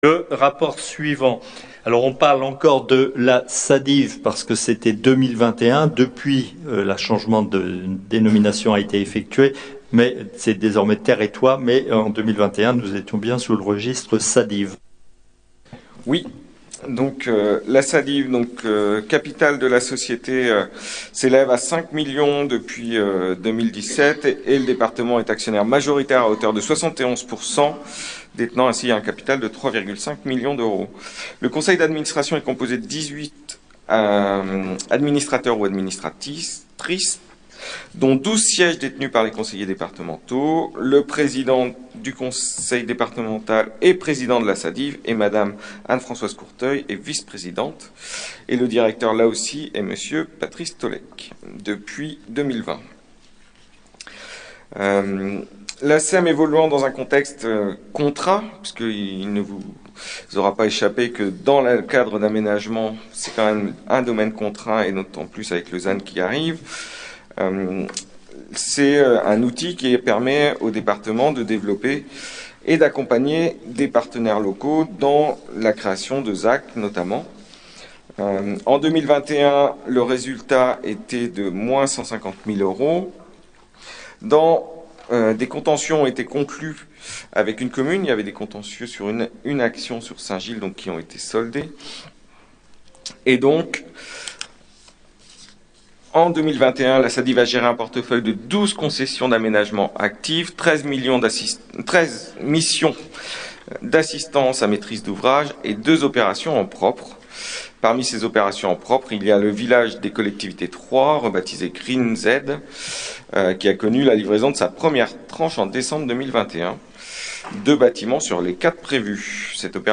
• Assemblée départementale du 17/11/22